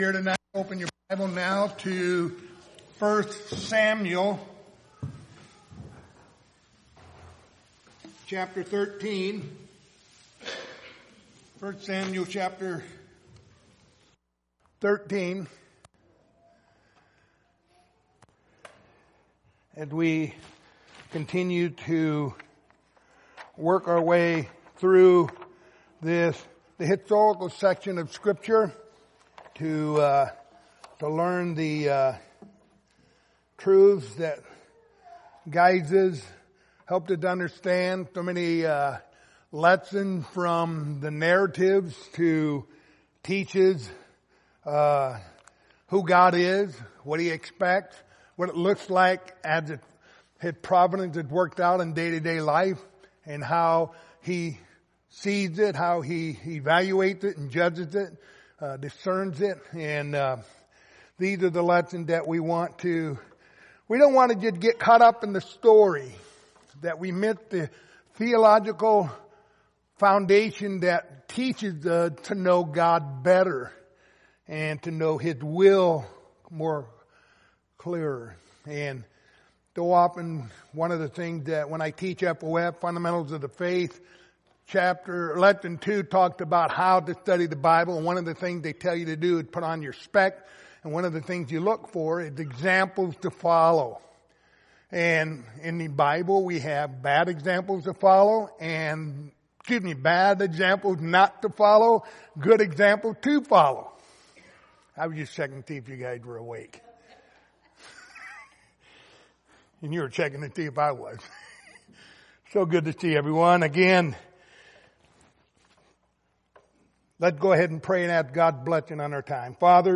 Passage: 1 Samuel 13:1-23 Service Type: Wednesday Evening